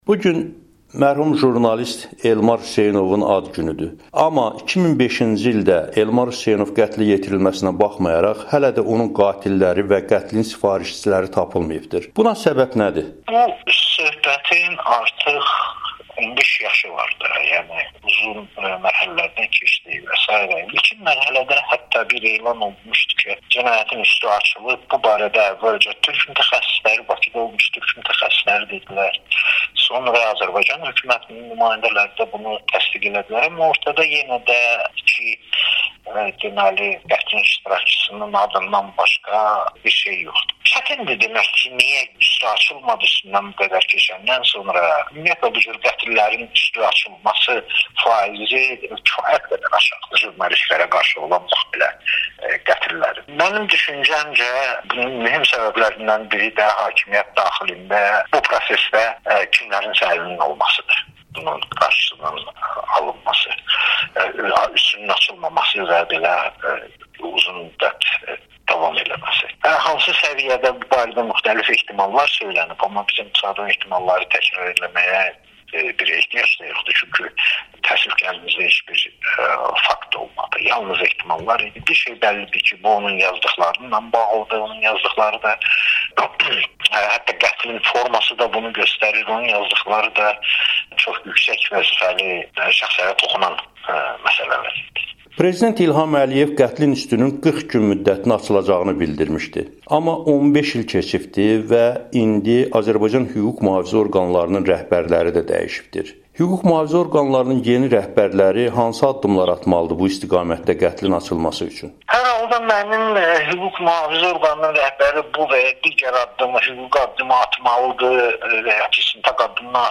müsahibəsində